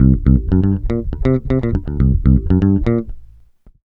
FUNK120.wav